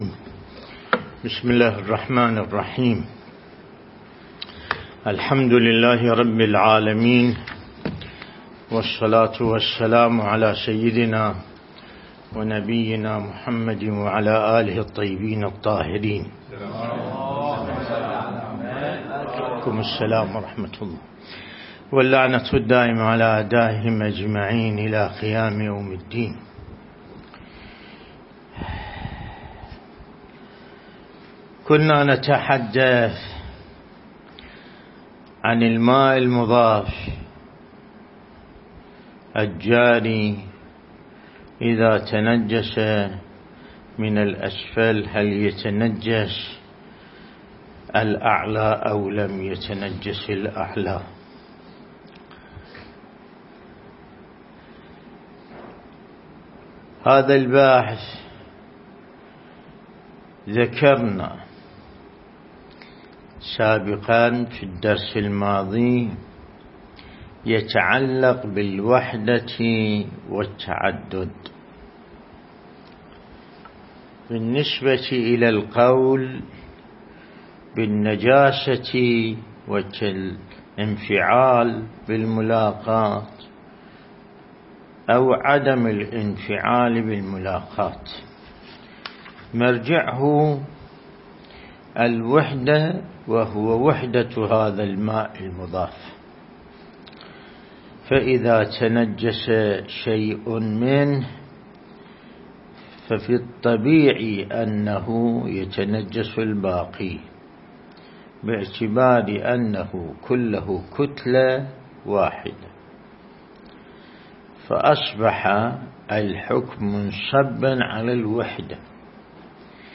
الدرس الاستدلالي شرح بحث الطهارة من كتاب العروة الوثقى لسماحة آية الله السيد ياسين الموسوي (دام ظله)